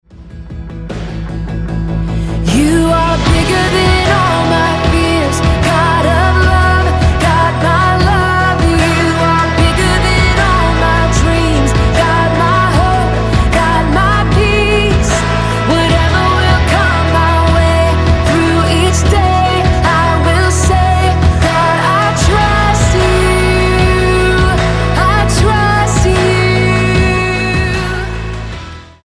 STYLE: Pop
characteristically powerful vocals take centre stage here